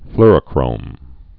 (flrə-krōm, flôr-)